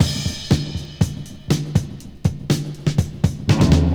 • 121 Bpm Drum Loop D Key.wav
Free drum loop - kick tuned to the D note. Loudest frequency: 791Hz
121-bpm-drum-loop-d-key-0H0.wav